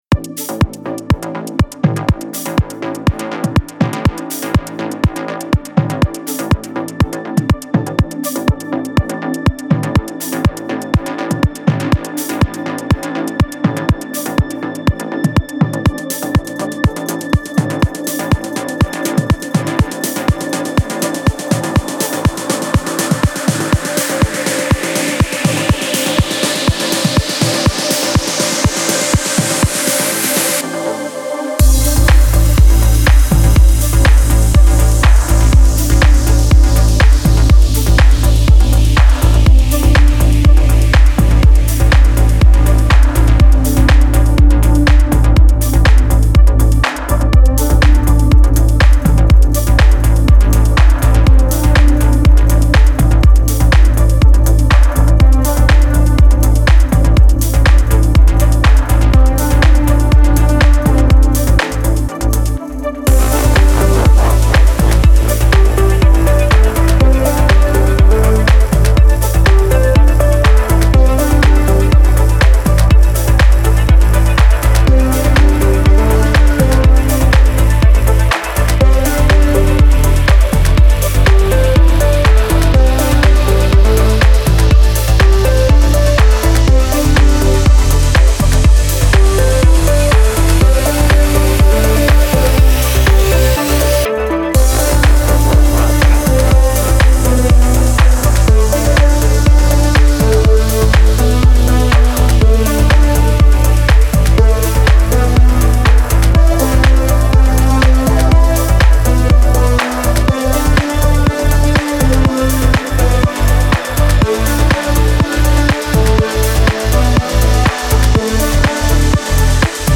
موسیقی بی کلام پر‌انرژی